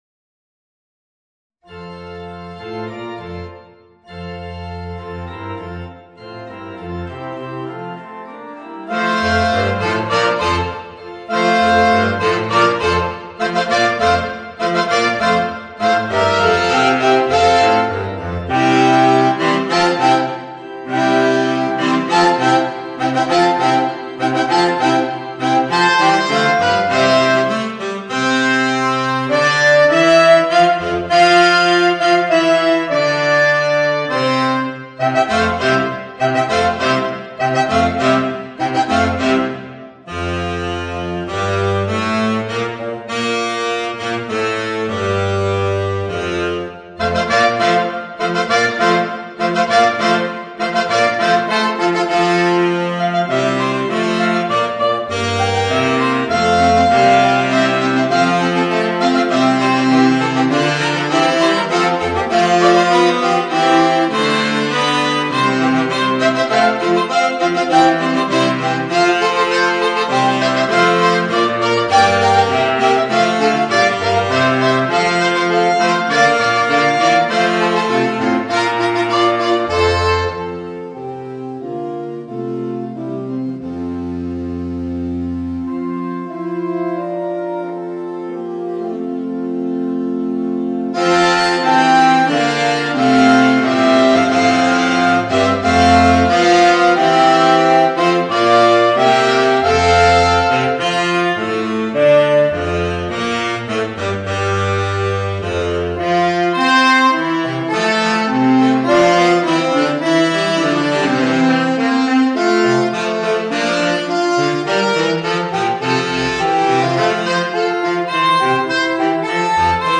Voicing: 4 Saxophones